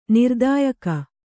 ♪ nirdāyaka